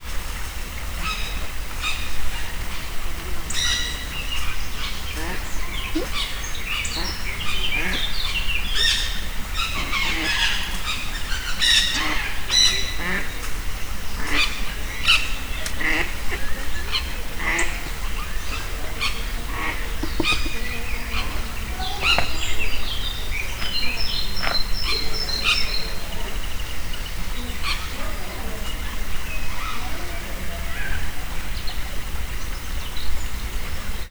toparttokesrece_vizcsobogas00.37.wav